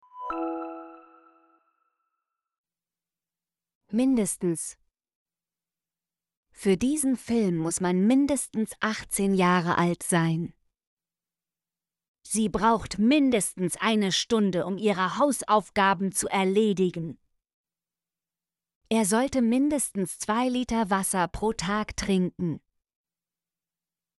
mindestens - Example Sentences & Pronunciation, German Frequency List